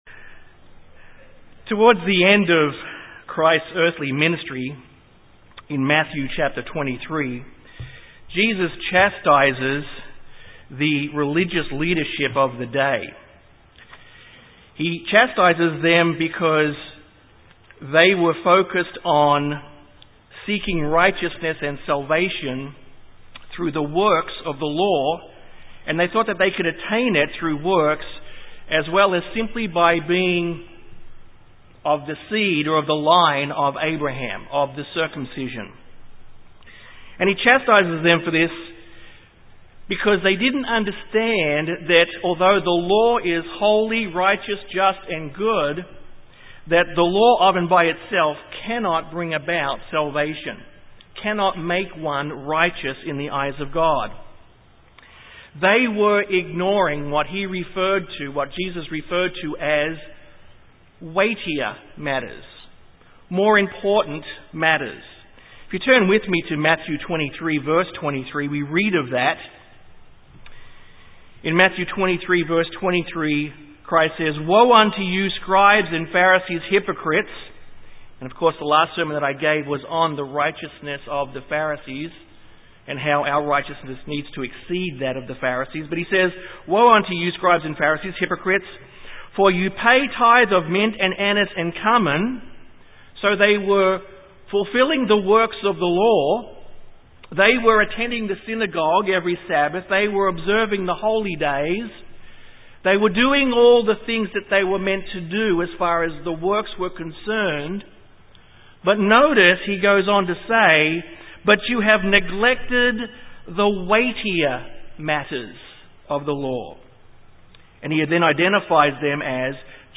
This sermon Identifies what Faith is. In the scripture, Faith is defined as Belief that leads to Trust that leads to Obedience. Faith is not just an intellectual concept but also includes the will to act in obedience to God.